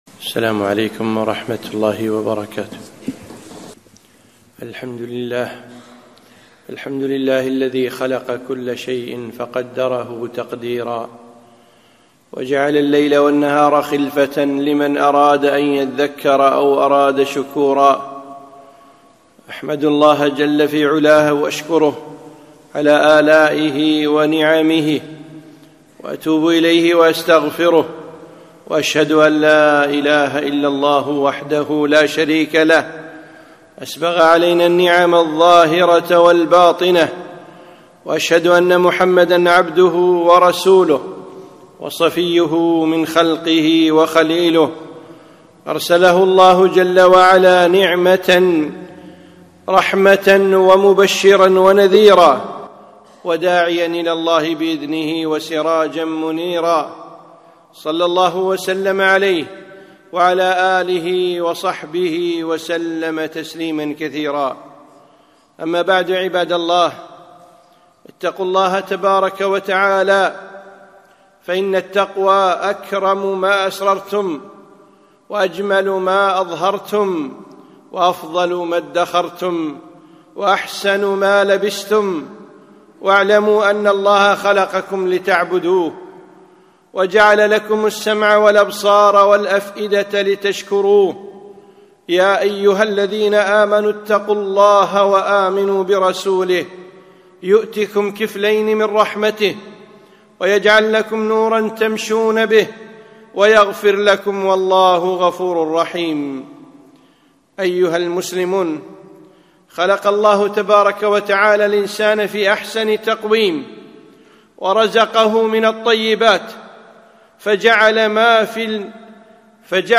خطبة - السيارات